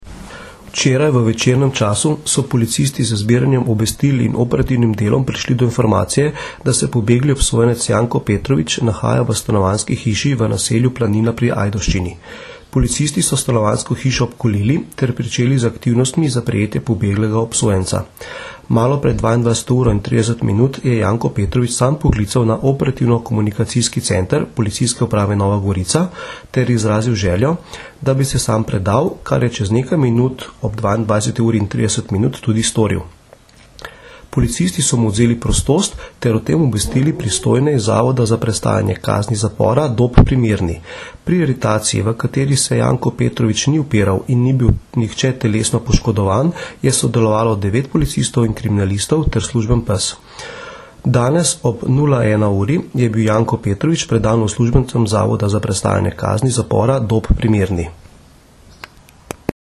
Zvočni posnetek izjave za javnostZvočni posnetek izjave za javnost (mp3)